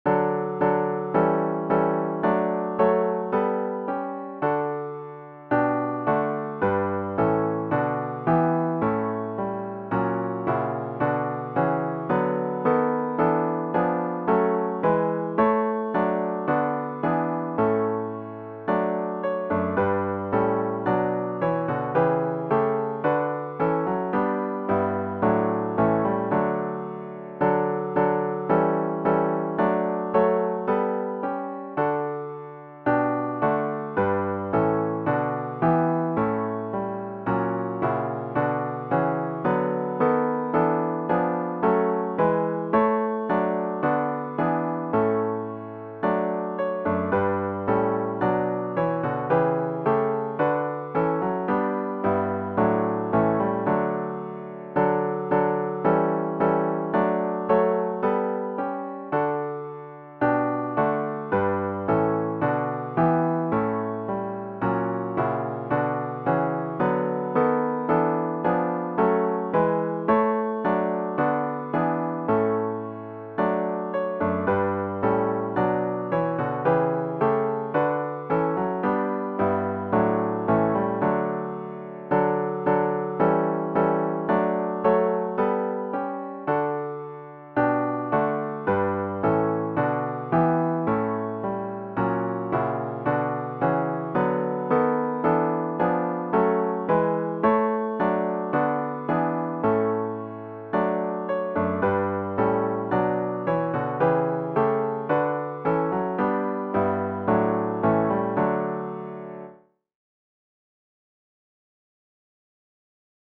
OPENING HYMN “Praise, My Soul, the God of Heaven” GtG 619
zz-619-Praise-My-Soul-the-God-of-Heaven-4vs-piano-only.mp3